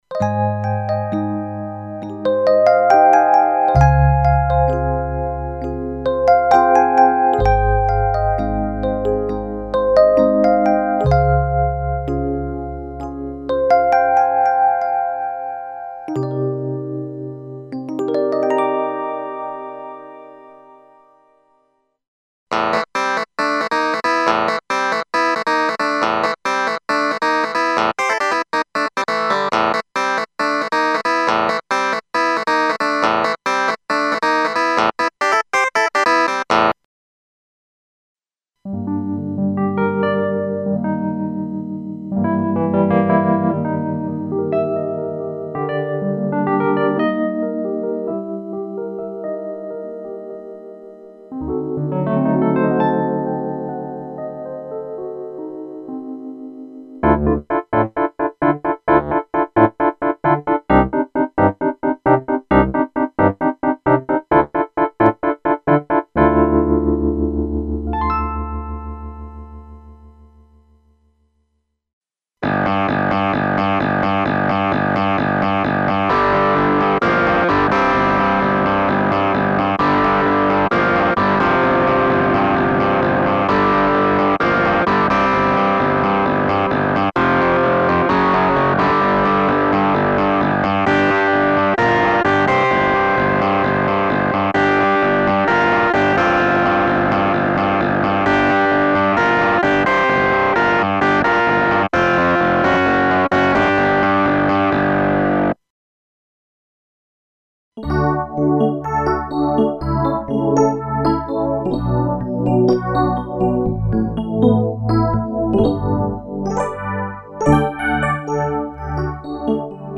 Exclusive collection of clavinet and electric piano sound programs (Rhodes, Wurlitzer, Yamaha CP80, Hohner Pianet and FM piano emulations) including a large number of specially modulated (filter, shaper, distortion, etc.) clavinet and electric piano sounds, carefully designed for various music styles.